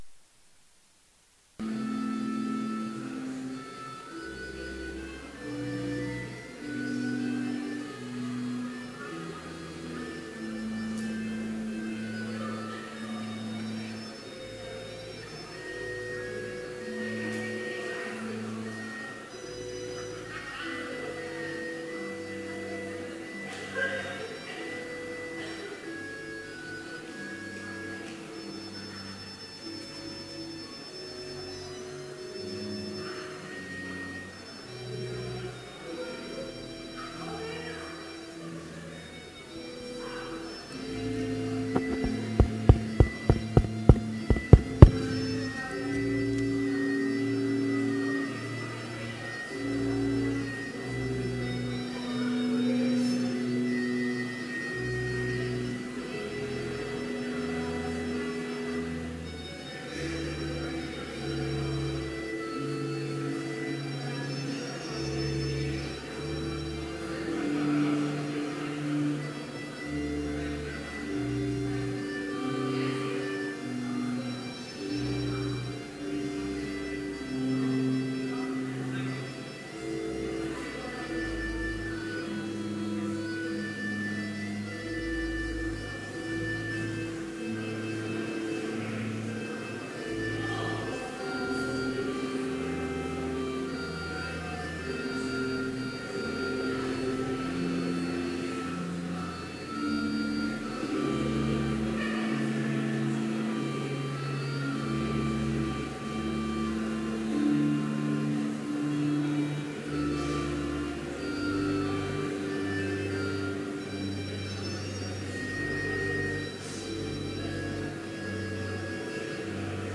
Complete service audio for Chapel - October 3, 2016